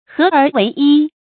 合而為一 注音： ㄏㄜˊ ㄦˊ ㄨㄟˊ ㄧ 讀音讀法： 意思解釋： 兩個或更多的事物合成一個。